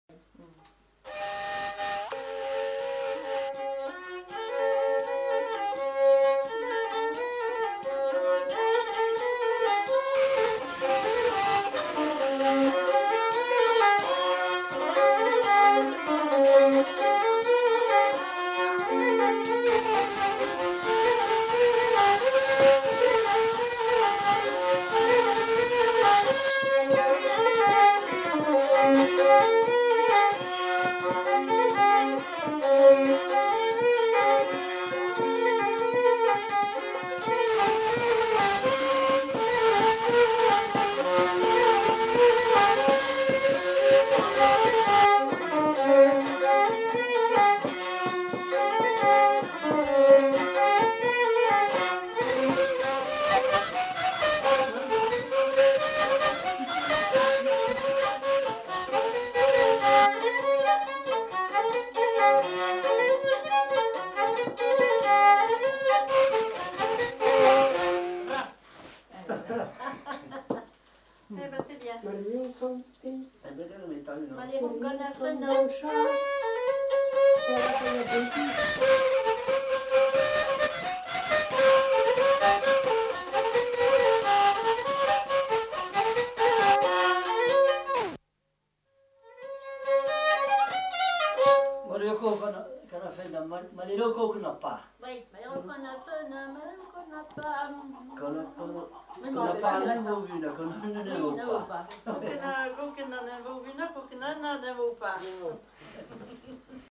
Aire culturelle : Limousin
Lieu : Lacombe (lieu-dit)
Genre : morceau instrumental
Instrument de musique : violon
Danse : bourrée
Notes consultables : Le second violon est joué par un des enquêteurs. Coupure en milieu de séquence.